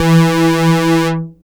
72.09 BASS.wav